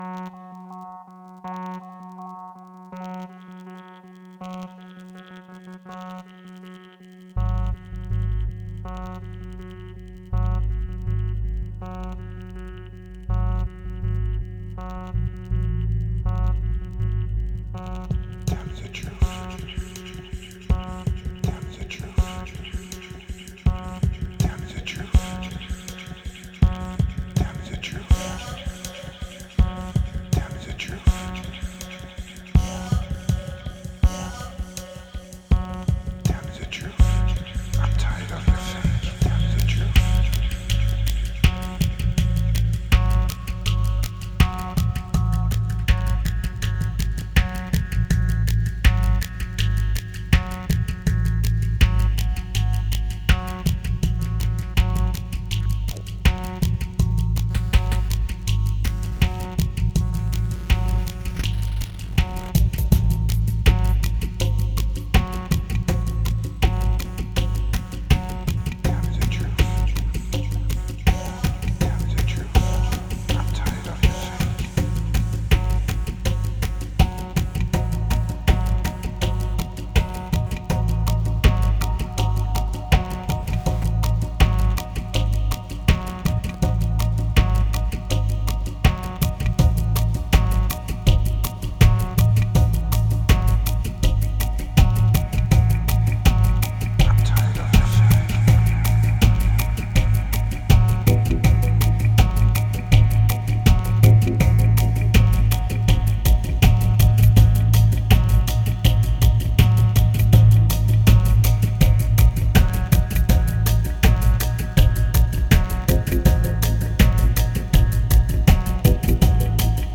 1942📈 - 34%🤔 - 81BPM🔊 - 2013-04-19📅 - -15🌟